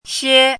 怎么读
xiē suò
xie1.mp3